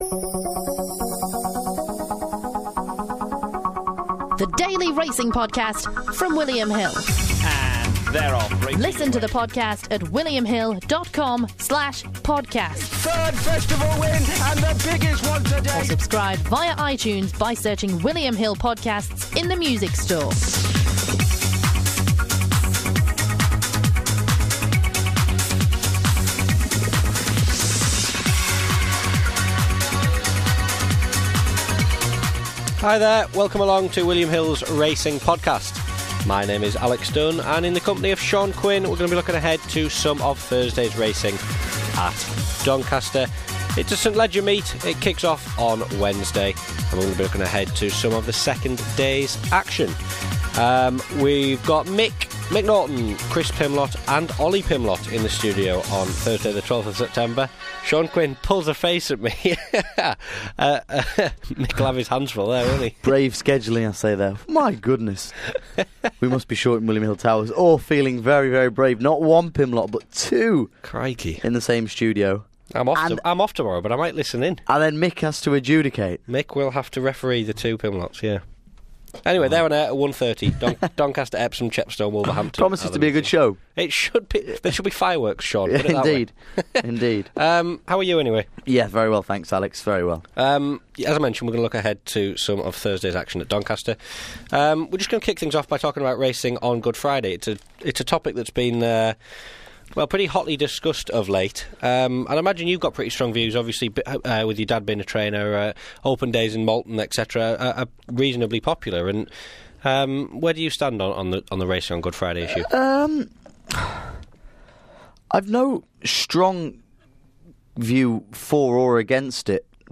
William Hill Radio studio